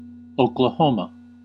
Uttal
Uttal US US: IPA : /ˌoʊk.ləˈhoʊ.mə/ Förkortningar OK Ordet hittades på dessa språk: engelska Översättning Egentliga substantiv 1. Oklahoma Definitioner Egentliga substantiv A state of the United States of America .